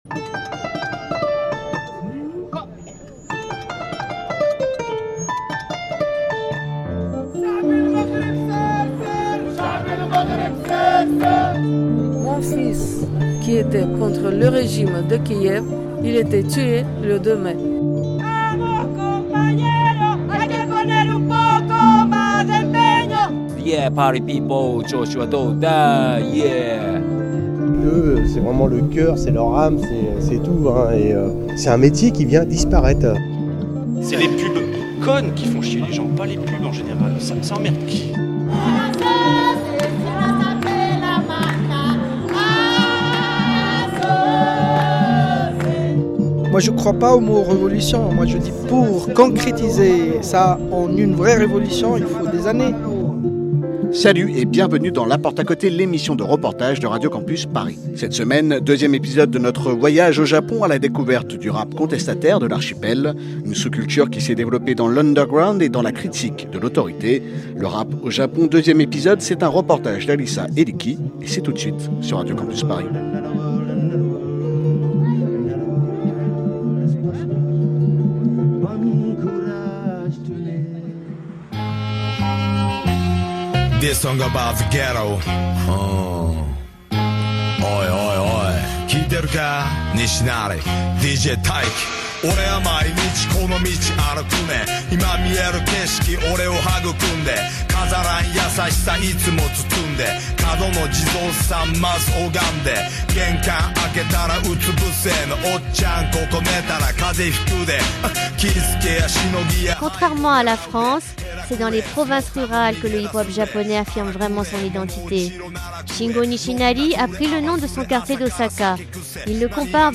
Reportage
Sortez donc, le sound system et les micro : direction l'est, tout droit jusqu’à Tokyo...